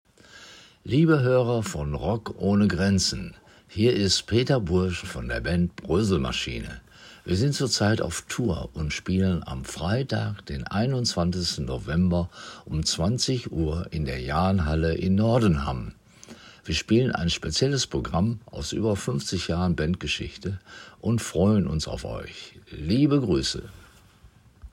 Rock-ohne-Grenzen.m4a